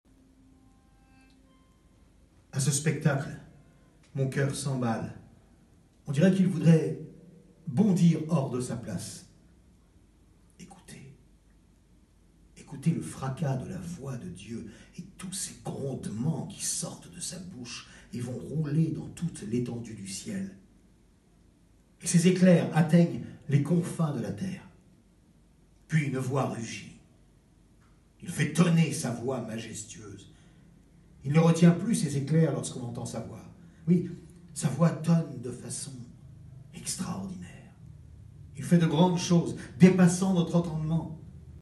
J’interprète un peu pour que ce soit visuel mais je ne change pas ma voix, j’essaie d’être clair et audible, juste de manière à ce qu’on distingue les personnages. En fait, j’ai une voix medium qui passe dans les graves et les aigus, j’interprète des rôles de 25 à 85 ans.